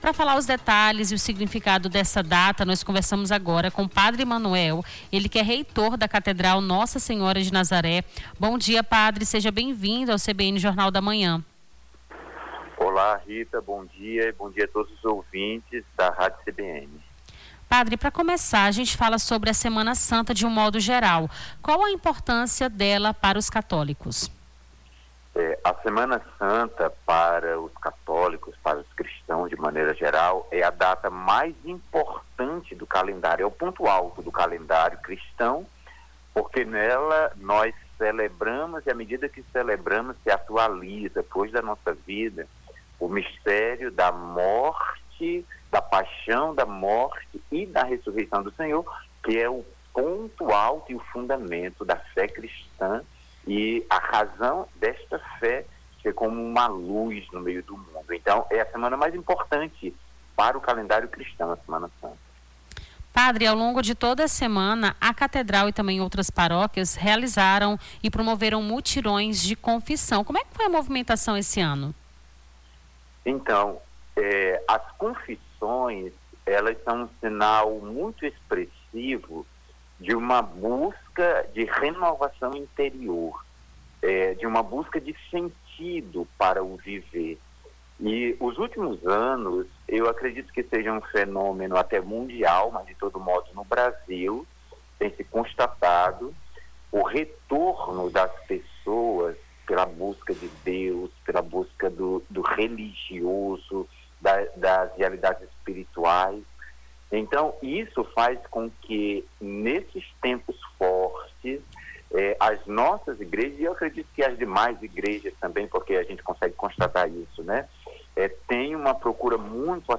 ENTREVISTA SEMANA SANTA - 03-04-26.mp3